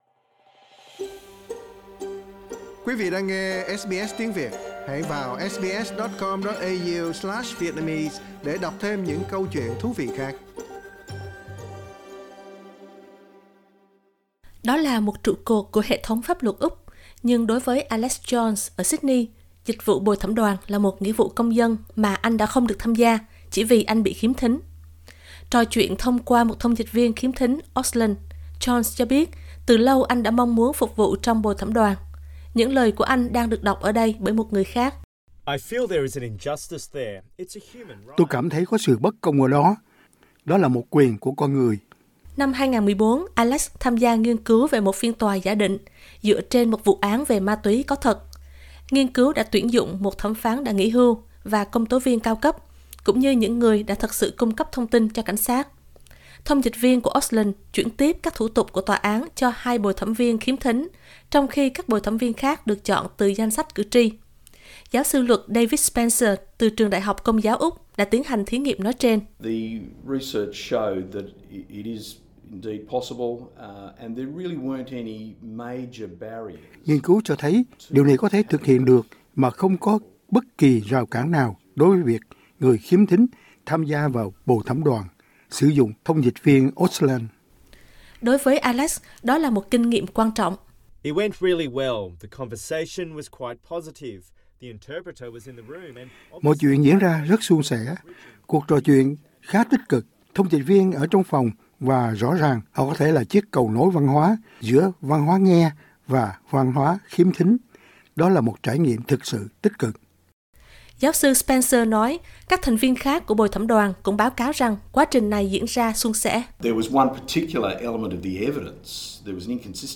Những lời của anh đang được đọc ở đây bởi một người khác.